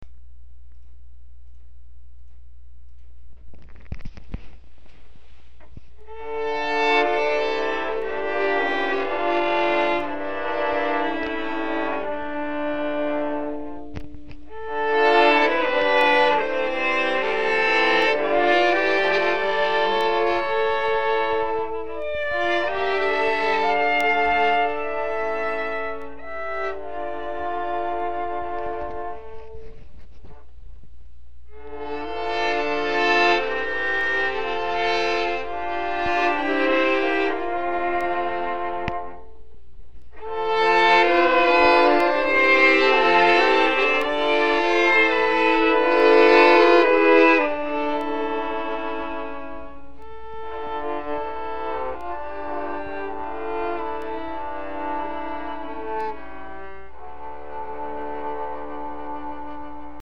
I am playing all four parts (on one instrument, my cello.)
This is recorded using the cruddiest recording device in the world. That is, the little software that comes with windows, and a REALLY old TELEX mike.
I hear some sour notes. ;)
You need some proper mixing software, I'm guessing you layered it using Sound Recorder?